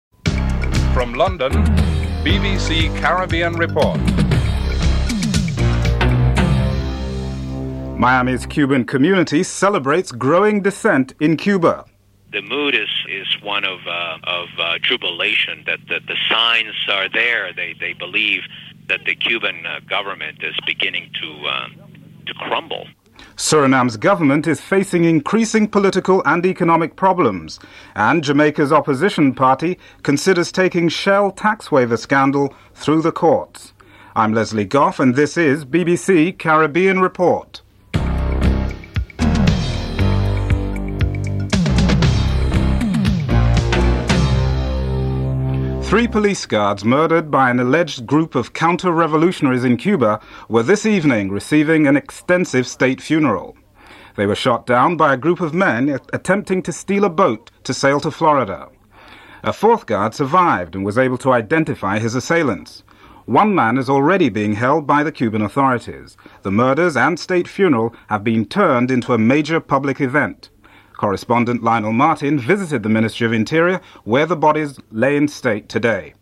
1. Headlines (00:00-00:44)
13. Interview with Oswald Harding, former Attorney General on his advice to the Jamaican Labour Party on the Shell Oil Company issue (12:26-14:25)